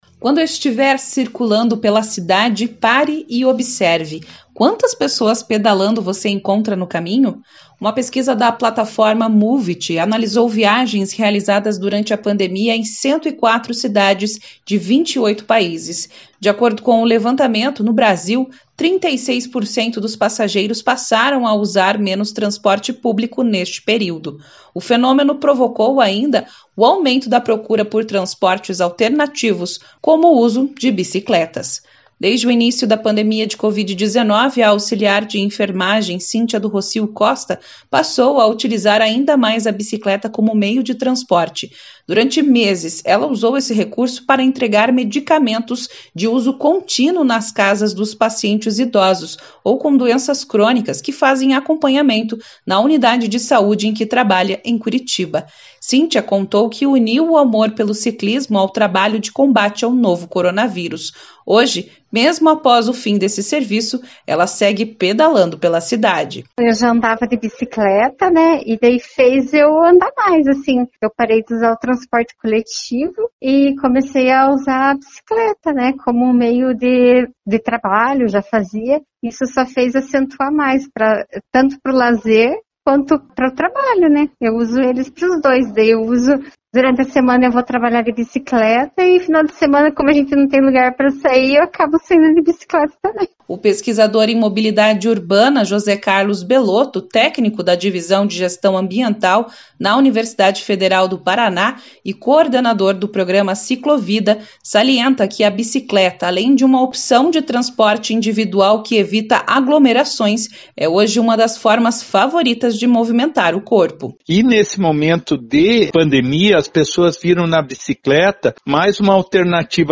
Uma tendência mundial: o uso da bicicleta como meio de transporte durante a pandemia de Covid-19. O veículo, já antes popular, virou moda entre as pessoas que querem manter o distanciamento social. Confira a reportagem